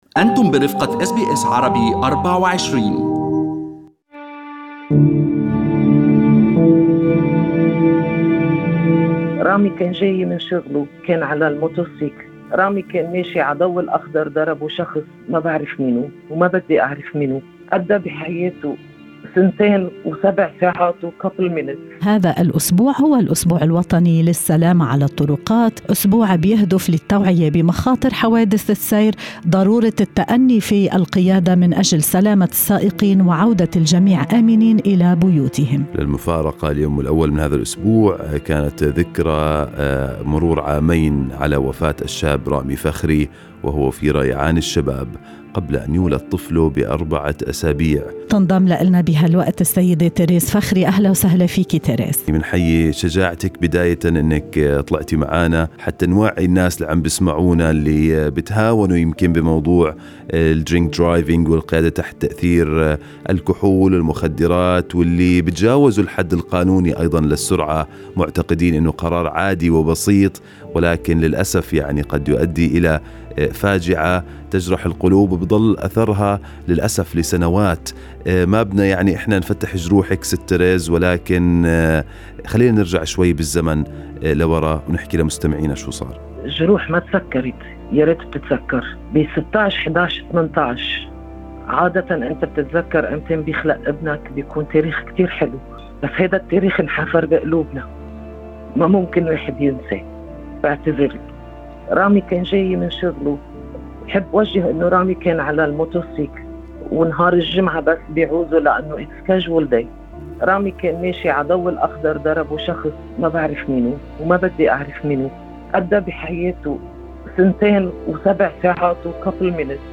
بصوت متقطّع يقطر ألماً ويشعّ ايماناً